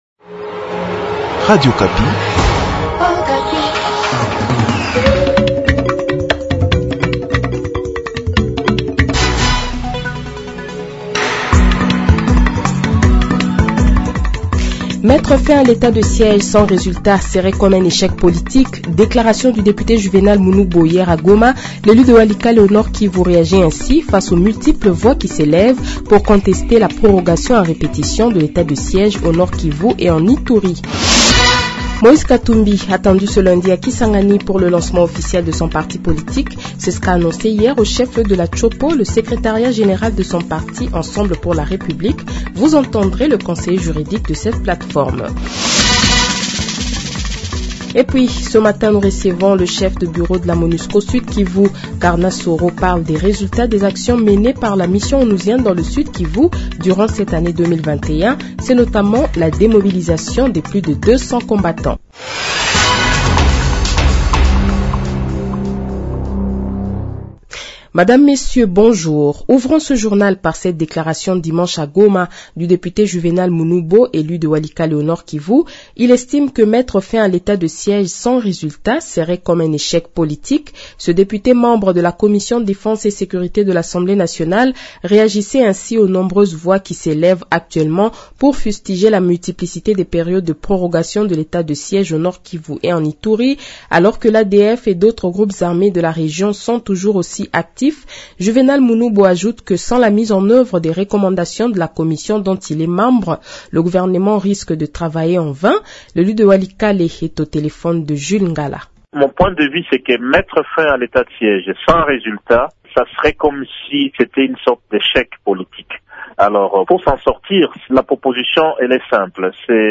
Le Journal de 7h, 20 Decembre 2021 :